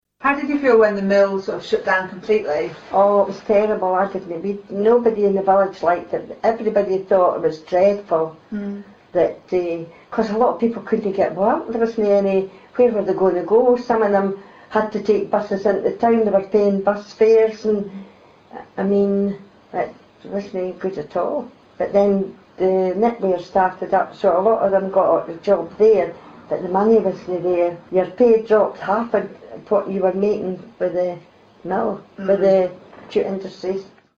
An oral history recording from former employees of Stanley Mills, one of the Industrial Revolution’s best-preserved relics.